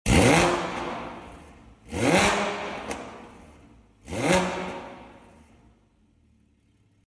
Our development manifolds on this 981 GT4 are sounding amazing!